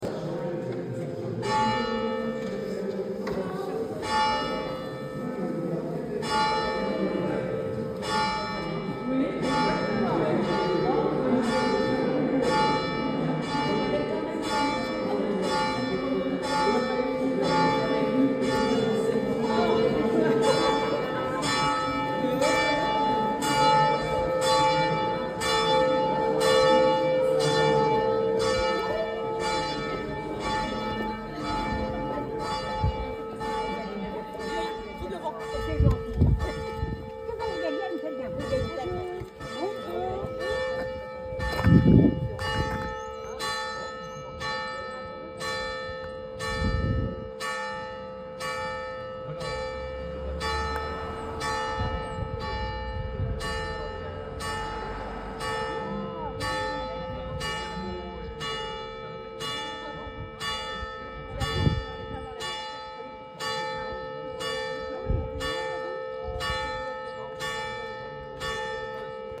Sons enregistrés lors de la cérémonie :
1ère sonnerie de cloche
2ème sonnerie de cloche (+ festive)